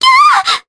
Ophelia-Vox_Damage_jp_03.wav